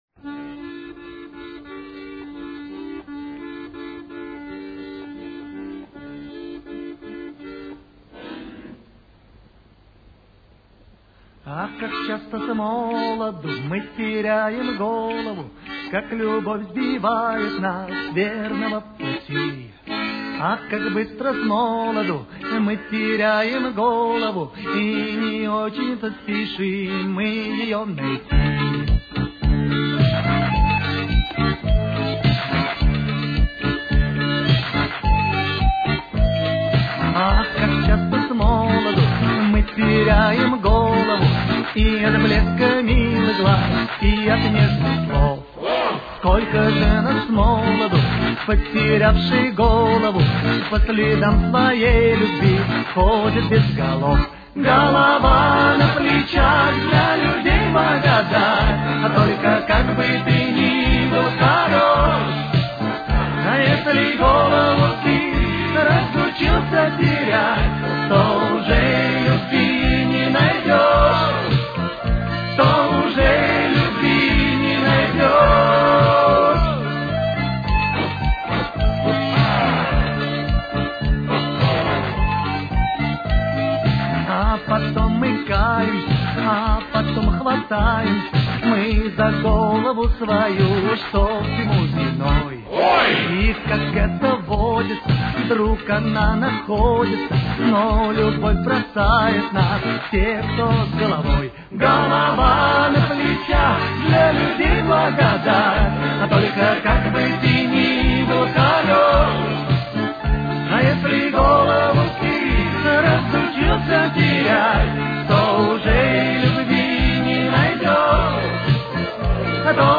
Темп: 109.